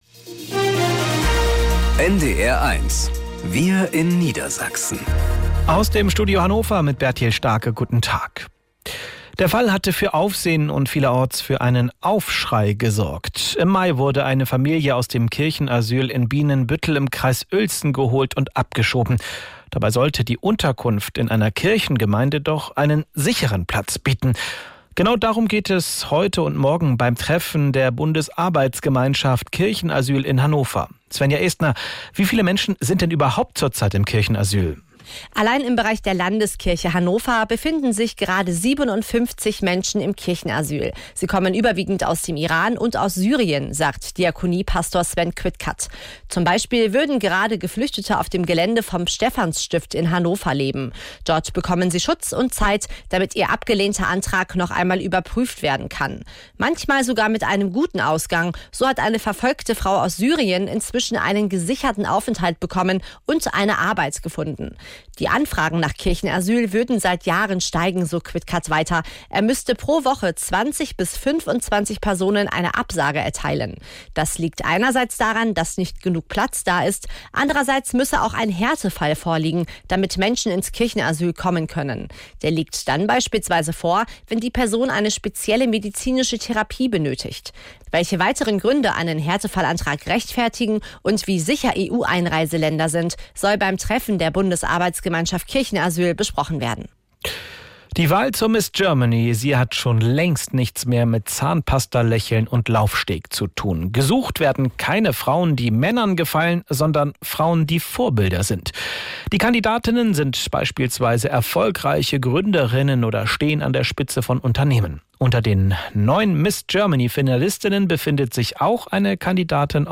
… continue reading 355 tập # Nachrichten # NDR 1 Niedersachsen # Tägliche Nachrichten